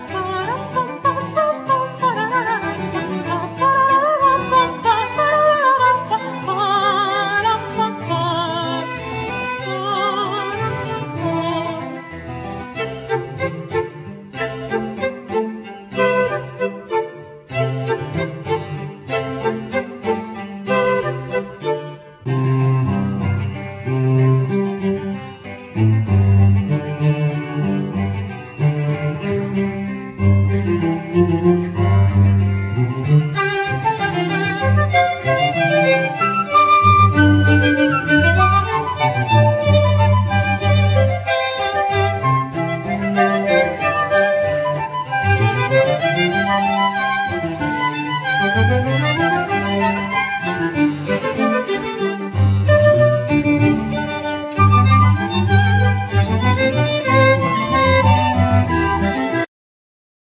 Voice
Flute
Clarinet
Keyboards
Violin
Cello